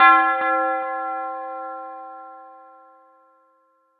Metro Church Bell.wav